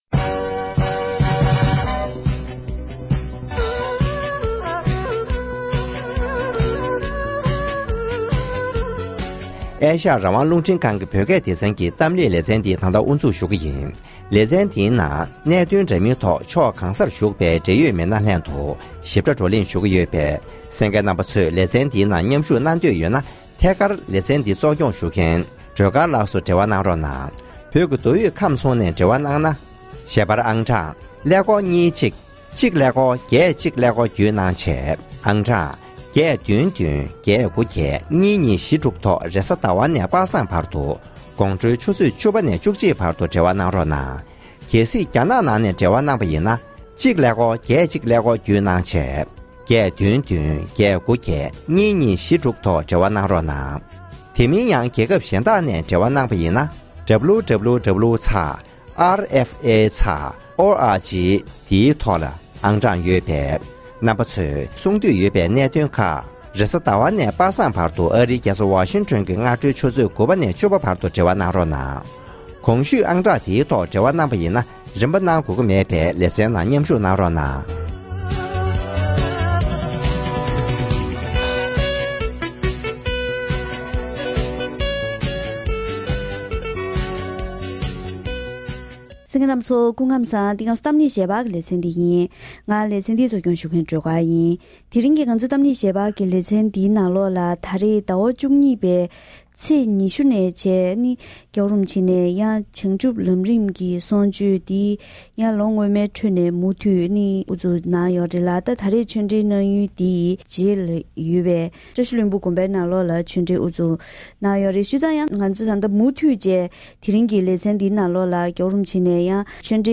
༄༅། །དེ་རིང་གི་གཏམ་གླེང་ཞལ་པར་ལེ་ཚན་ནང་རྒྱ་གར་ལྷོ་ཕྱོགས་སུ་ཡོད་པའི་བྱེས་ཀྱི་བཀྲ་ཤིས་ལྷུན་པོ་དགོན་པར་བྱང་ཆུབ་ལམ་རིམ་གྱི་གསུང་ཆོས་སྐབས་སྤྱི་ནོར་༧གོང་ས་སྐྱབས་མགོན་ཆེན་པོ་མཆོག་ནས་སྤྱི་ཚོགས་ནང་བྱམས་བརྩེ་ཡར་རྒྱས་ཐོག་བུད་མེད་ཀྱིས་ནུས་པ་ཆེ་བ་ཐོན་རྒྱུ་ཡིན་པས། མ་འོངས་ཡང་སྲིད་དུ་བུད་མེད་ཡོང་སྲིད་པའི་སྐོར་སོགས་བཀའ་སློབ་གནང་བ་ཁག་ངོ་སྤྲོད་ཞུས་པ་ཞིག་གསན་རོགས་གནང་།